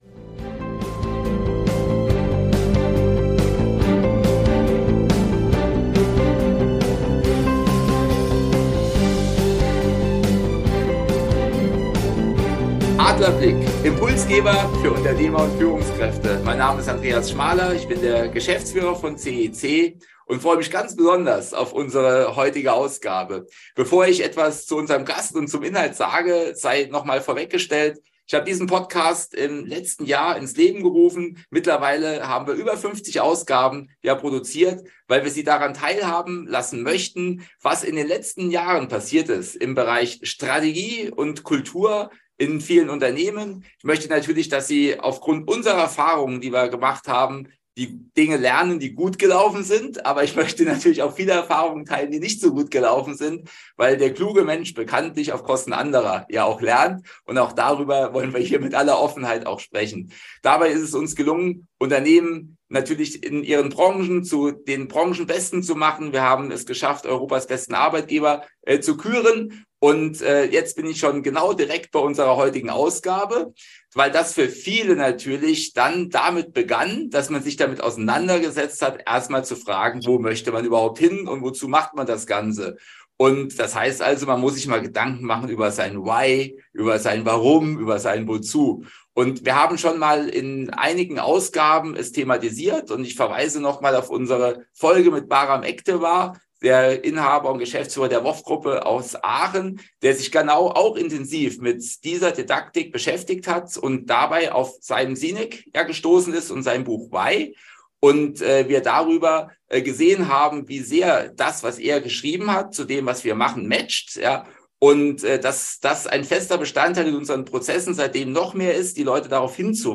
Beschreibung vor 3 Jahren Uns ist es gelungen, den aus den Medien sehr bekannten und präsenten Moderator Björn Brost, für unsere Podcast-Folge zu gewinnen.